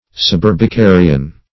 Suburbicarian \Sub*ur`bi*ca"ri*an\, Suburbicary